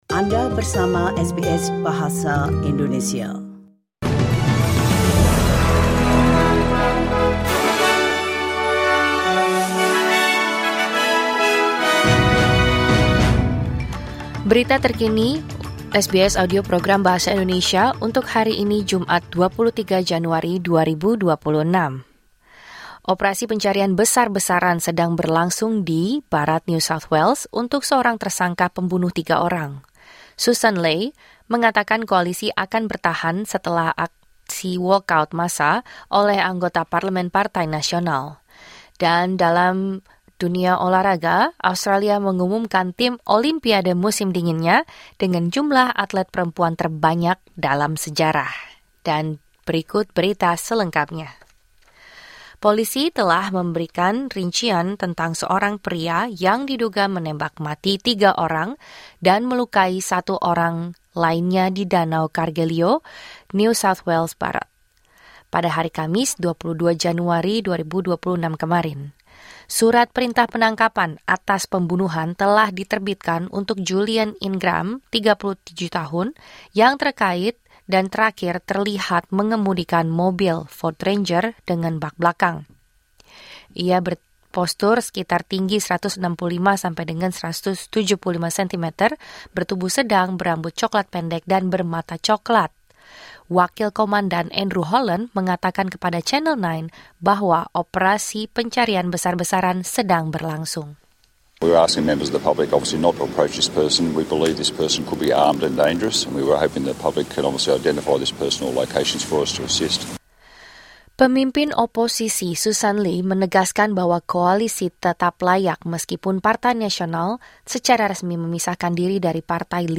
Berita Terkini SBS Audio Program Bahasa Indonesia - Jumat 23 Januari 2026